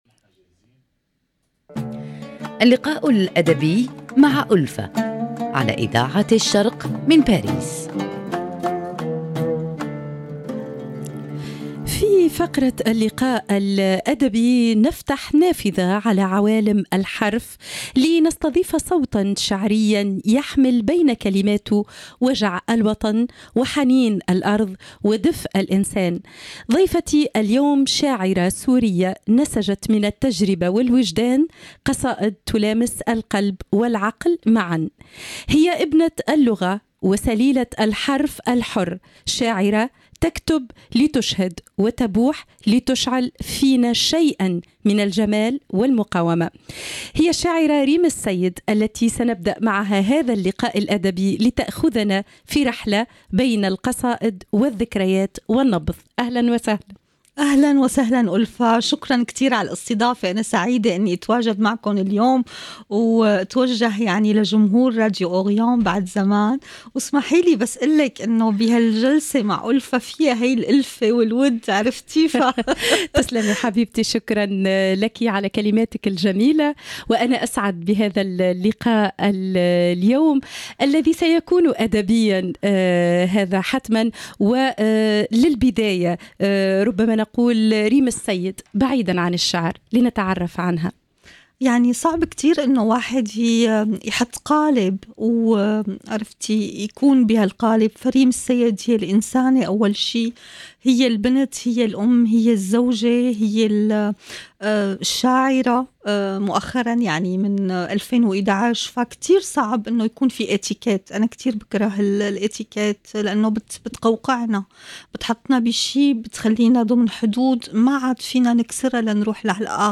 فقرة اللقاء الأدبي